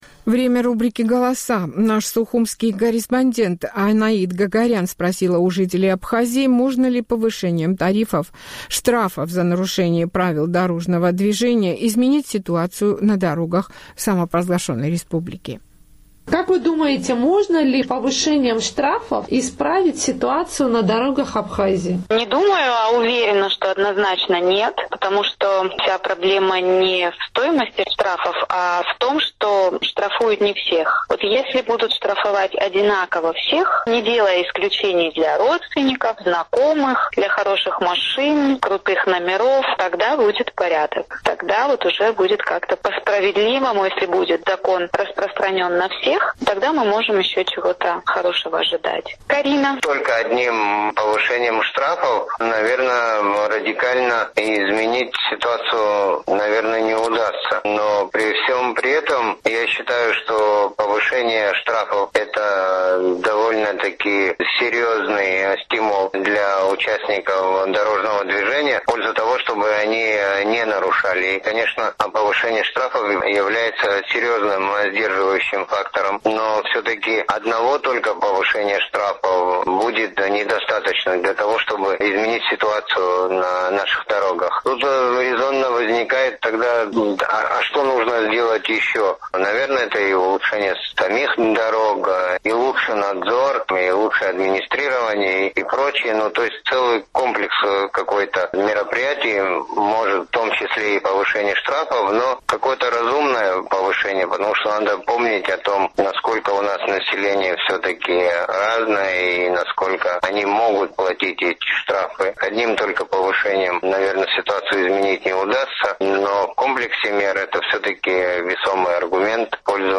Голоса
«Эхо Кавказа» спросило у жителей Абхазии, можно ли повышением штрафов за нарушением правил дорожного движения изменить ситуацию на дорогах республики.